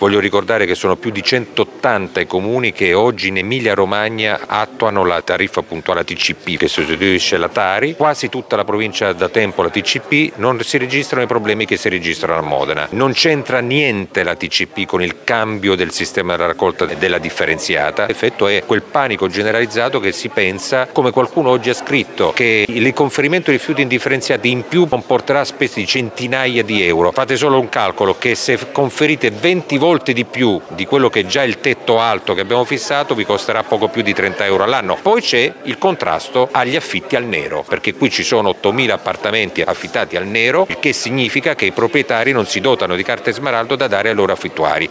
“Così solo a Modena, la tariffa puntuale però non c’entra nulla” – replica il sindaco Massimo Mezzetti: